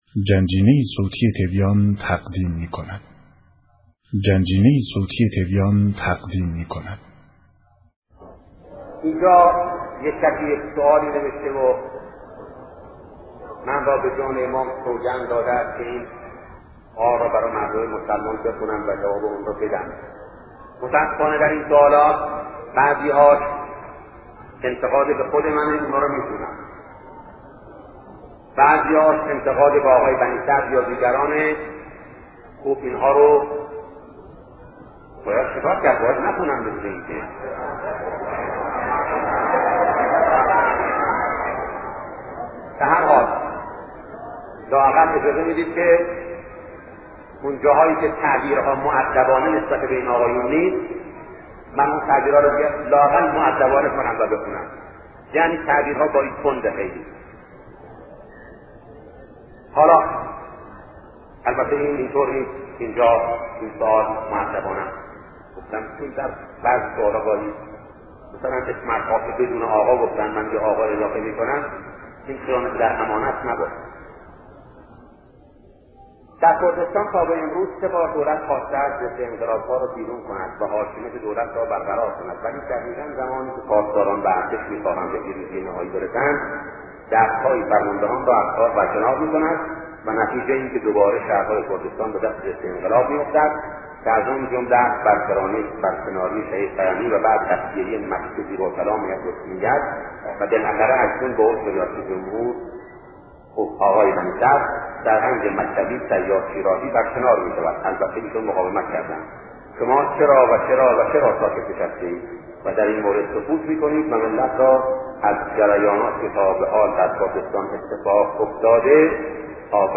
صوت سخنرانی شهید بهشتی- آیا تحزب موجب تفرقه در جامعه میشود؟-بخش‌دوم